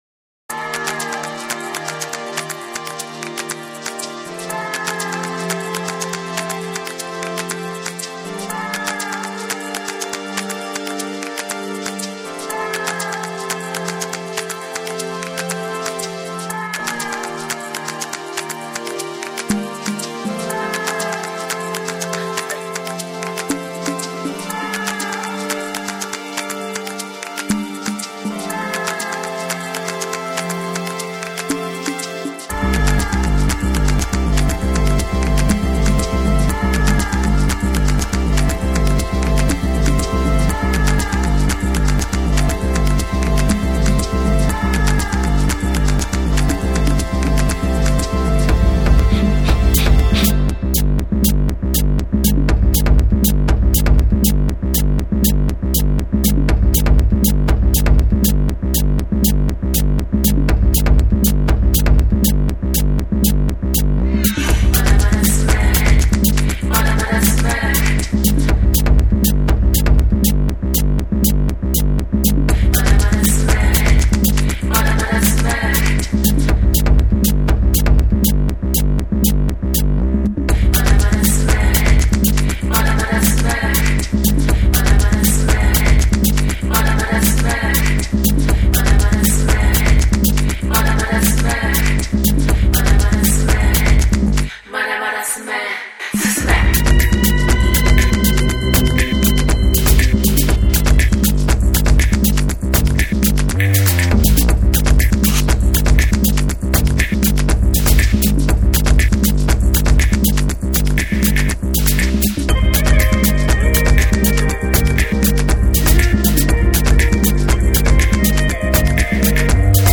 太いベースラインと、トライバル感
JAPANESE / BREAKBEATS